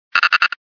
Cri de Grainipiot dans Pokémon Diamant et Perle.